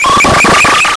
AggressiveTransmission.wav